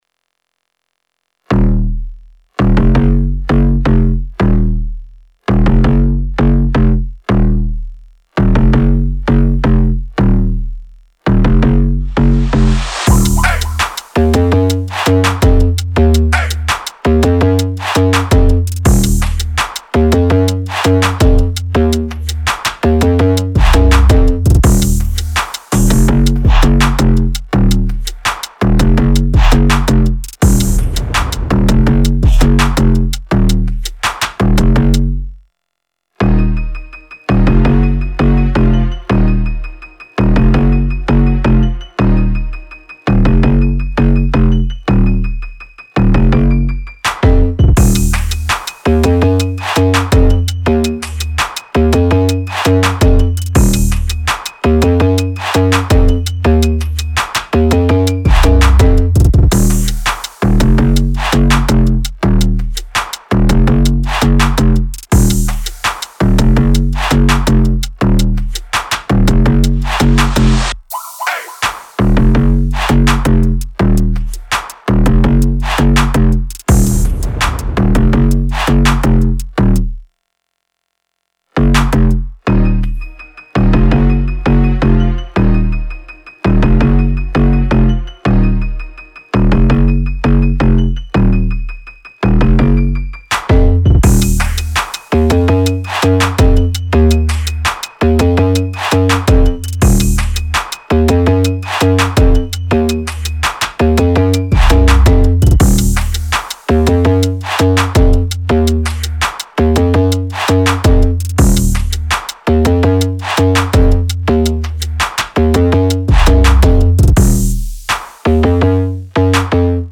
Musica di sottofondo